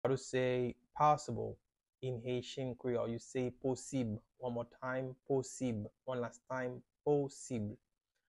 “Possible” in Haitian Creole – “Posib” pronunciation by a native Haitian Teacher
“Posib” Pronunciation in Haitian Creole by a native Haitian can be heard in the audio here or in the video below: